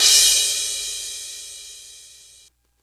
• Huge Drum Crash Sample E Key 08.wav
Royality free crash cymbal sound sample tuned to the E note. Loudest frequency: 6112Hz
huge-drum-crash-sample-e-key-08-IjS.wav